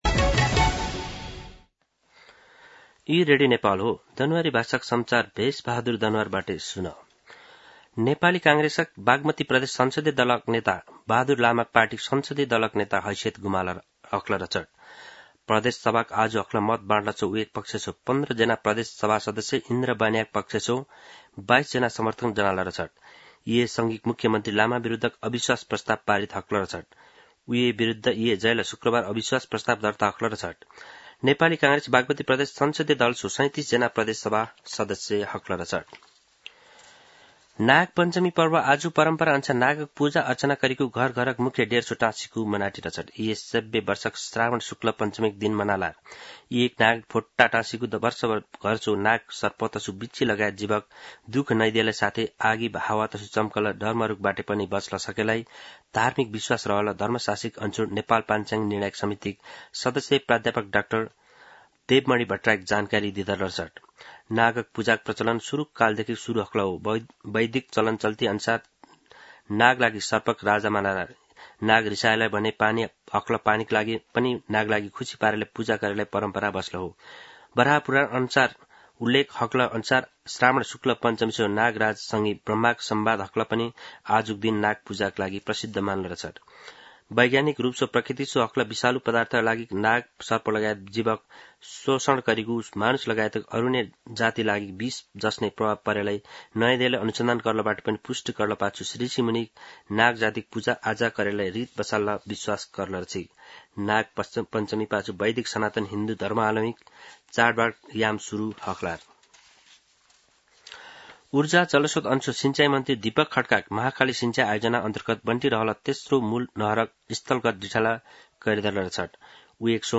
दनुवार भाषामा समाचार : १३ साउन , २०८२
Danuwar-News-04-13.mp3